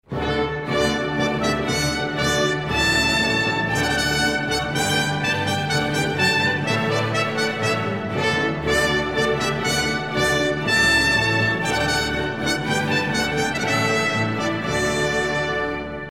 Un petit quizz (auditif) sur la musique classique